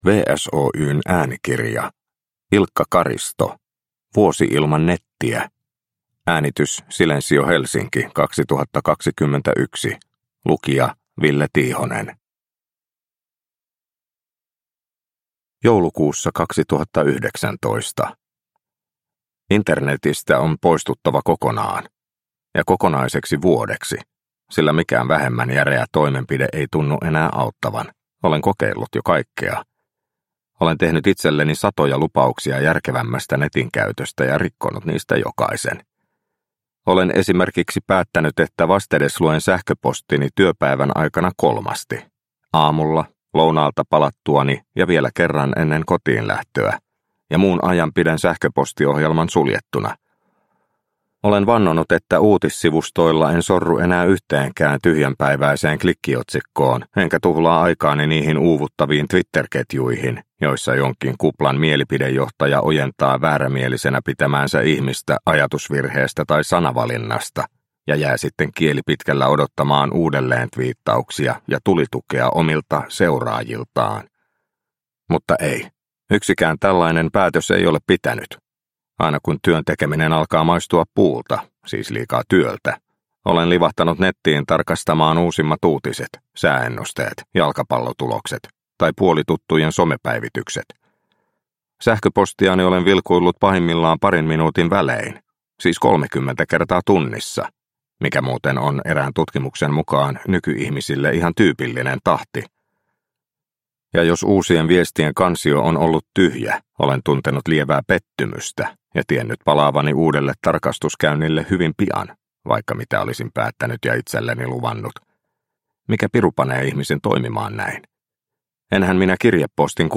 Vuosi ilman nettiä – Ljudbok – Laddas ner